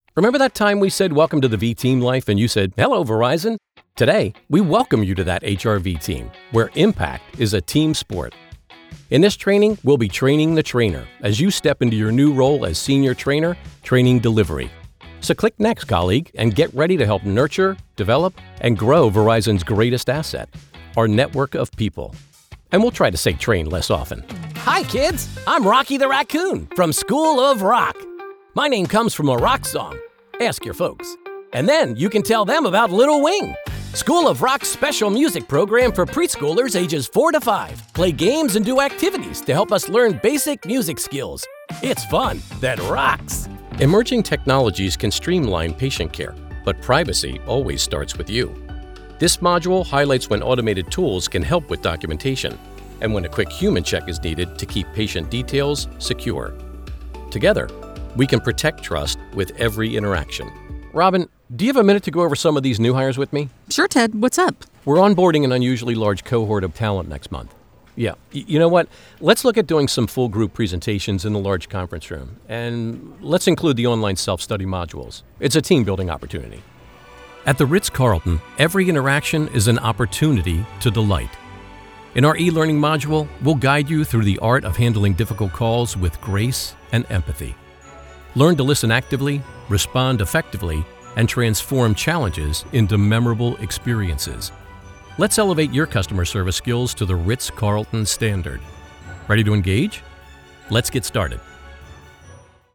eLearning
• Sennheiser MKH416
• Professional Recording Booth
• Authentic, Approachable, Conversational and Friendly